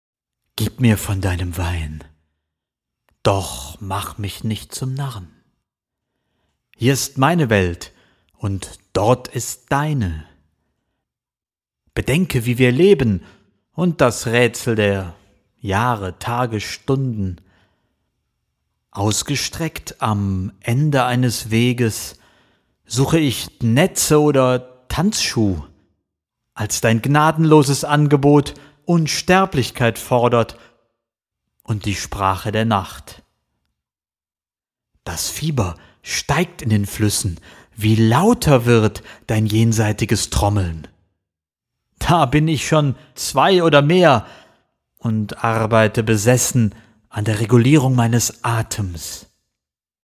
Sprechprobe: eLearning (Muttersprache):
German voice over talent and musician